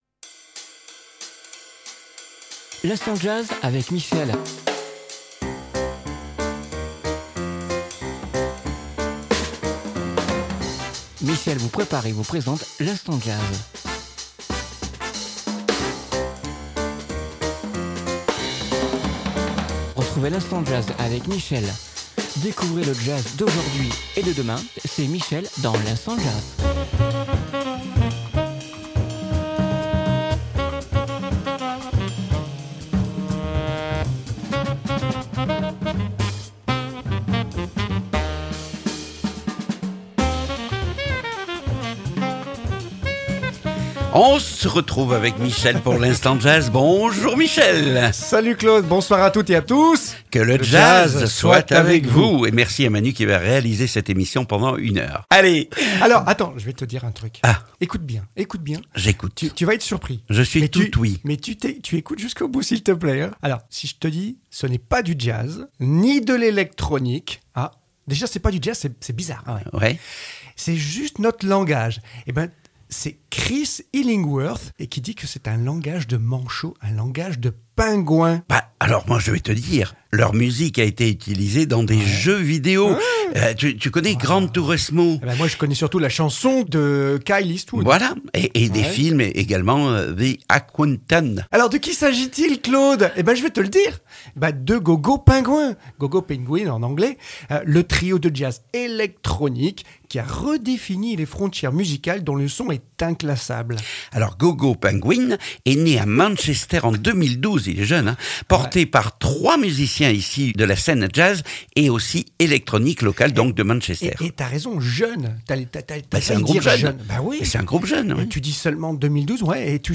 trio instrumental
Une ambiance contemplative et presque tragique